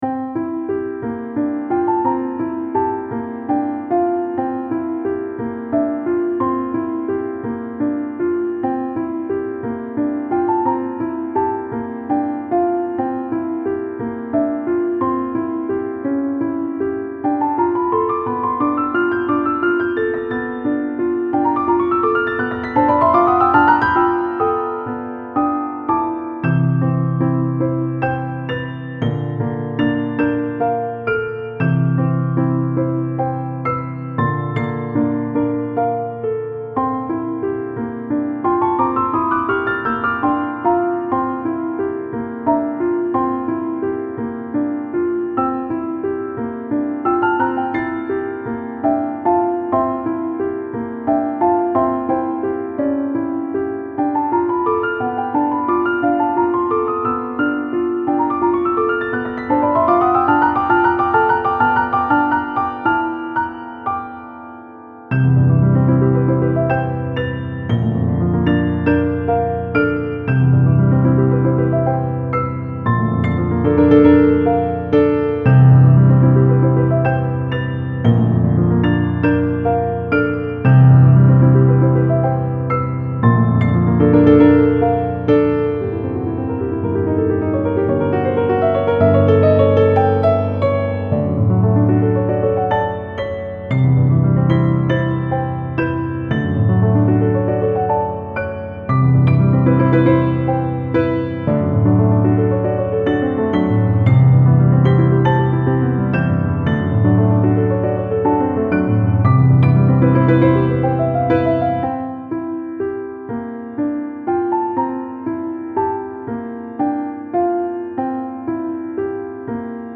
Style Style Classical
Mood Mood Calming, Intense
Featured Featured Piano